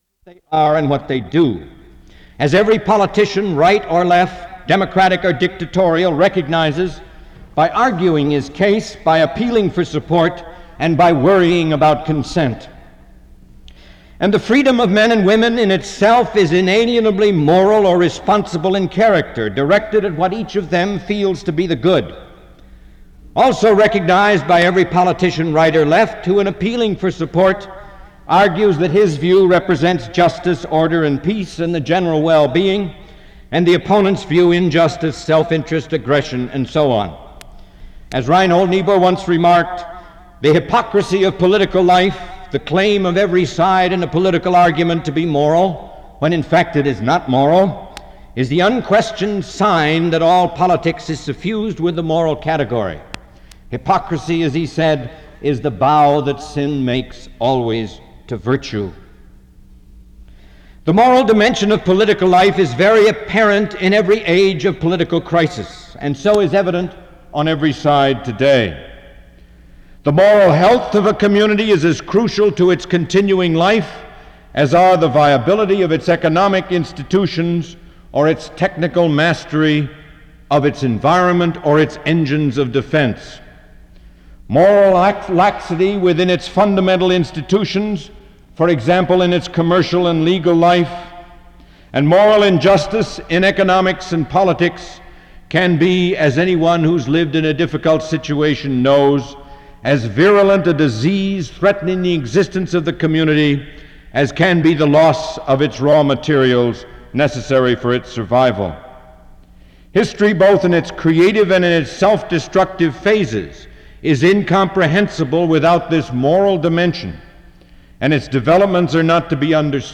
Langdon Gilkey was a professor at the University of Chicago.
SEBTS Chapel and Special Event Recordings - 1970s